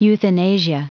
Prononciation du mot euthanasia en anglais (fichier audio)
Prononciation du mot : euthanasia